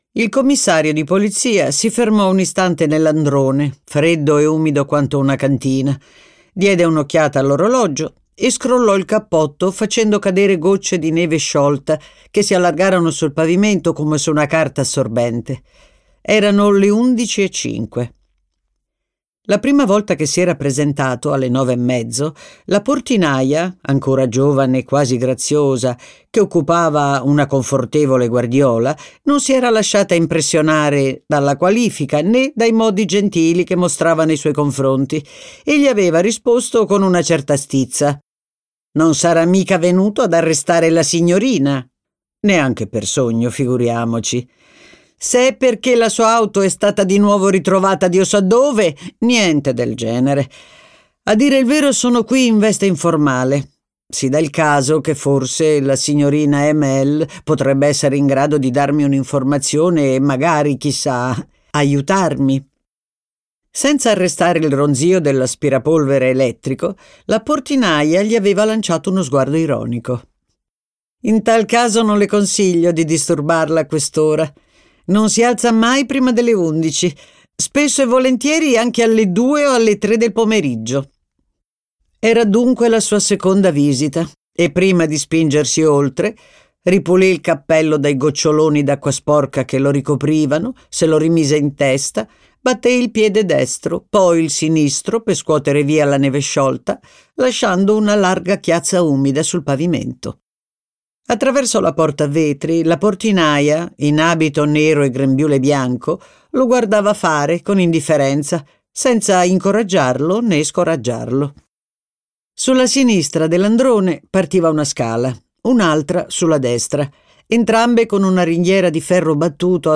letto da Anna Bonaiuto
Versione audiolibro integrale